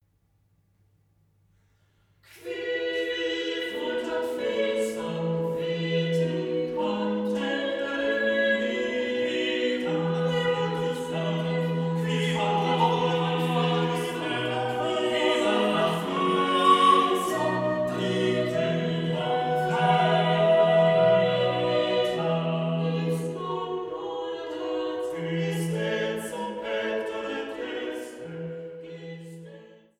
Leitung und Orgel